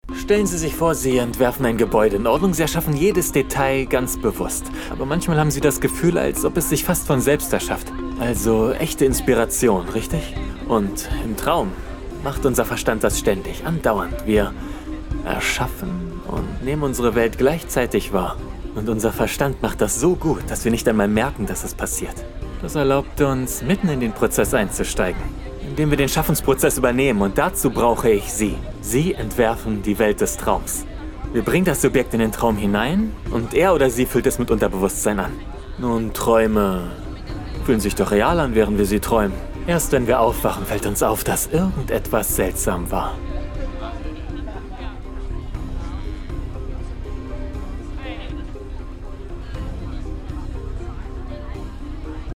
Synchronausschnitt